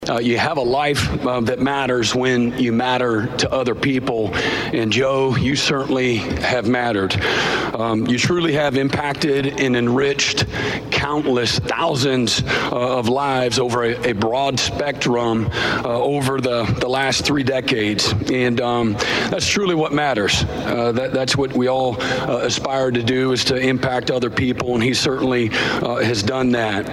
While at SEC Media Days in Atlanta, OU football coach Brent Venables talked about